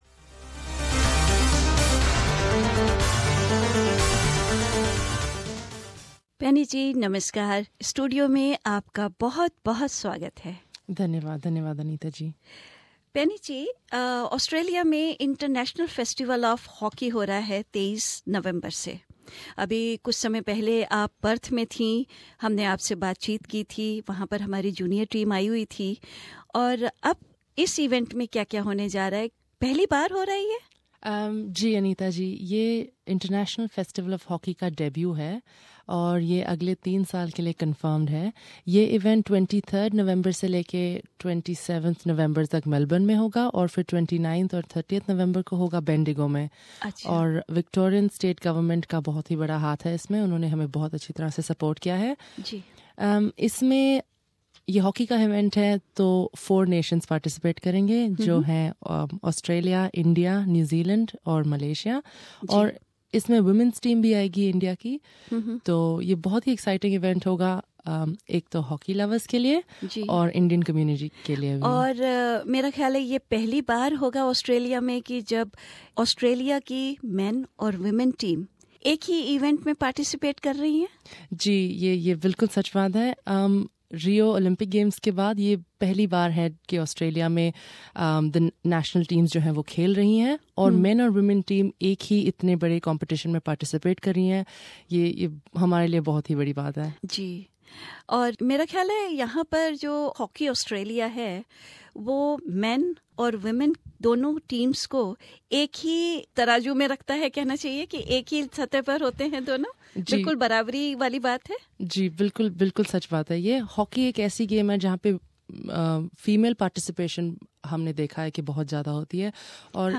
सुनिये यह बातचीत...